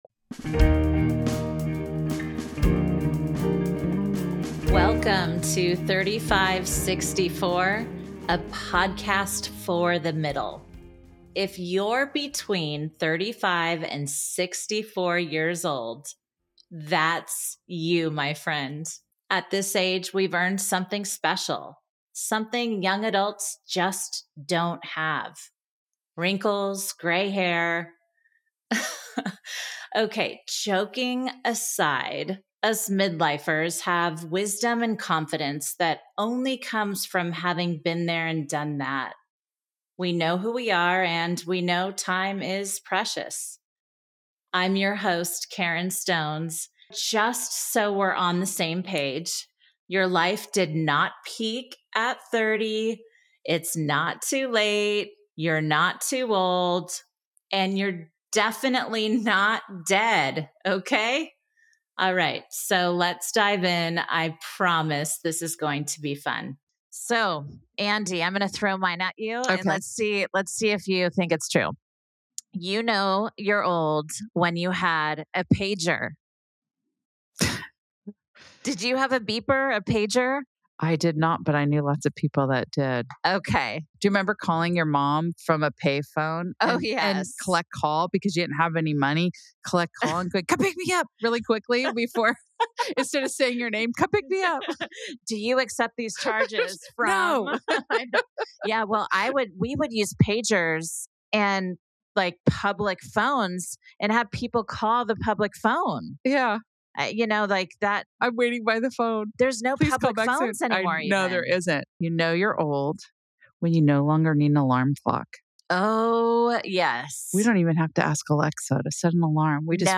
Ever wonder how we went from blasting boomboxes to bird-watching in the blink of an eye? In this clip compilation, we take a look back at some of the funny ‘You know you’re old when….” segments from the podcast.